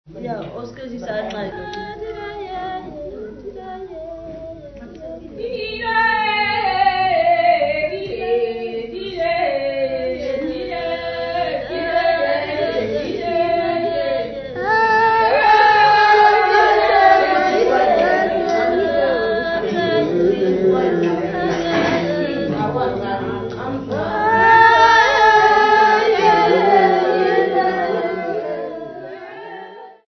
Folk music--Africa
Field recordings
sound recording-musical
Indigenous music.